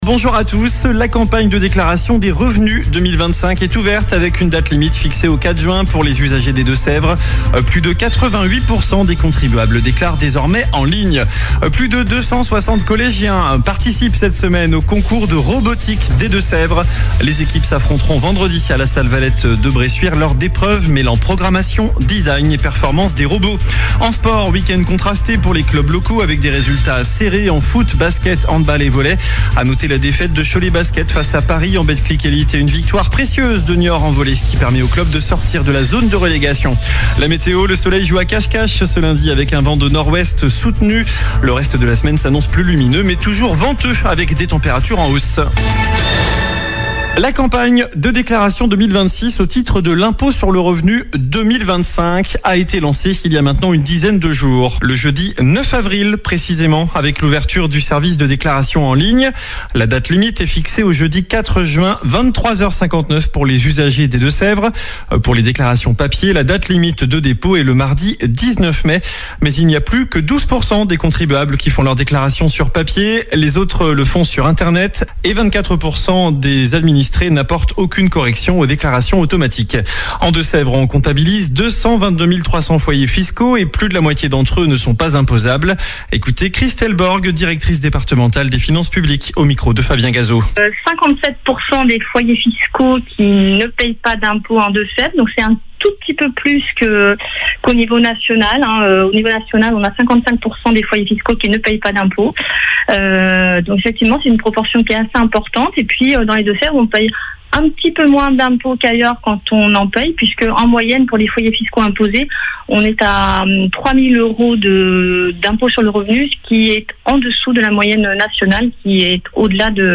Journal du lundi 20 avril (midi)